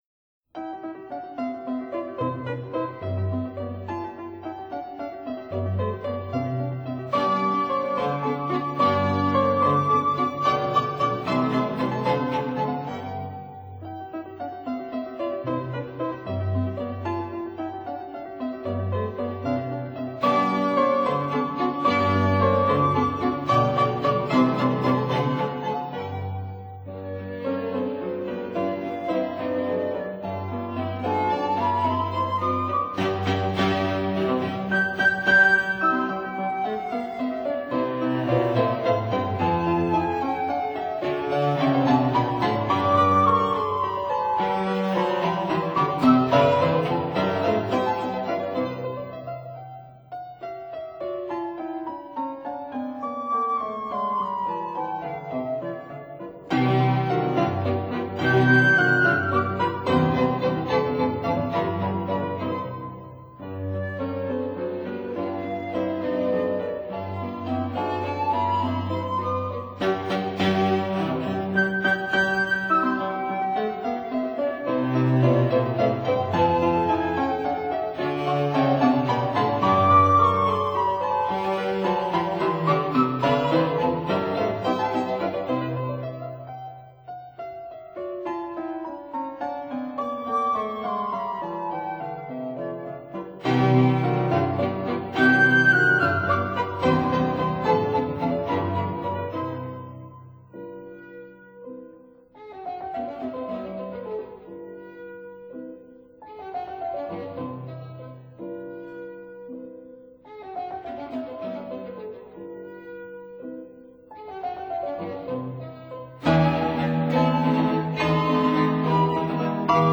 arranged for Piano, Flute, Violin & Cello by:
Fortepiano
Flute
Violin
Cello
(Period Instruments)